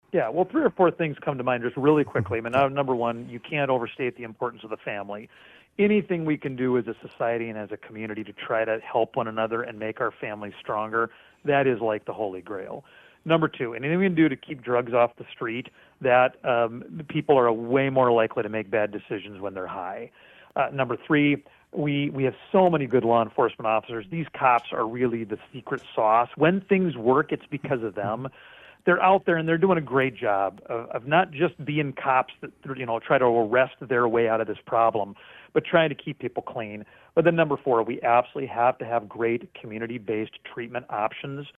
Johnson was asked about what the communities can do to help combat this.